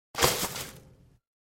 Здесь вы найдете как знакомые «хрустящие» эффекты корзины, так и более современные варианты.
Звук удаления мультимедийных файлов